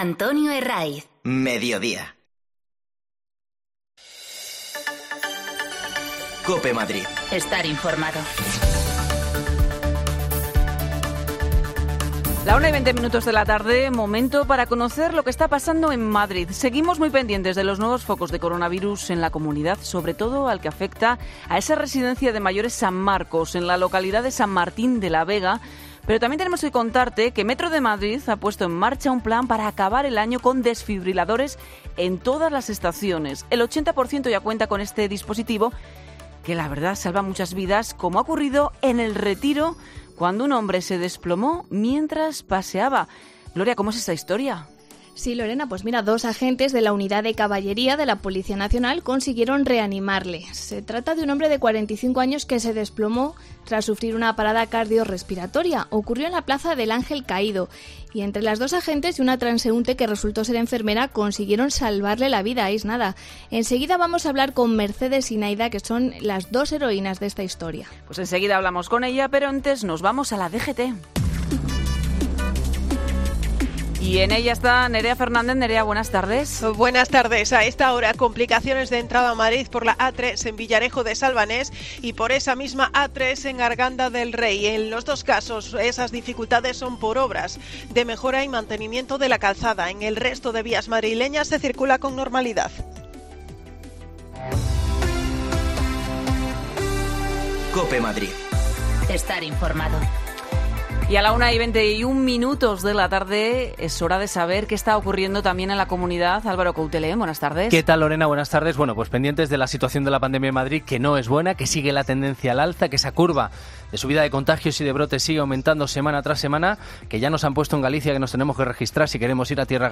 AUDIO: En Mediodía COPE Madrrid hablamos con las dos agentes de la Policía Nacional que reanimaron a un hombre en el Retiro que había sufrido un...